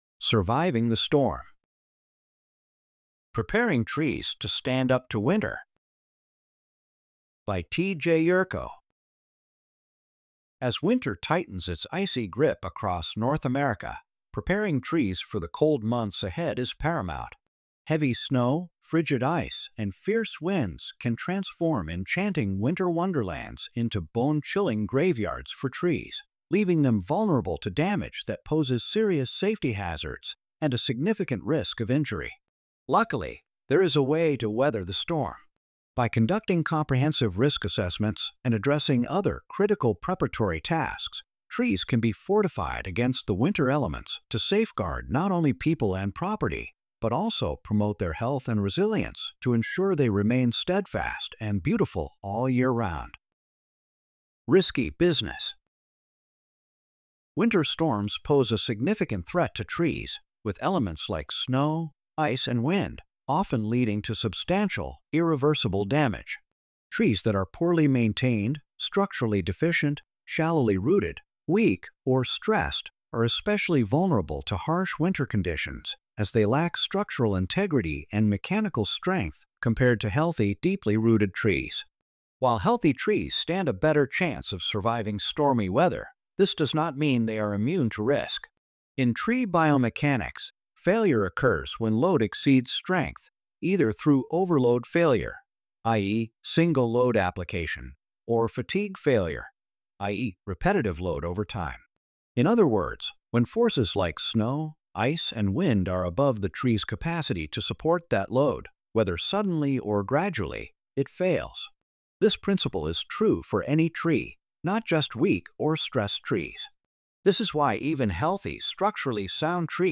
Este es nuestro intento de convertir las historias en audio español usando Inteligencia Artificial.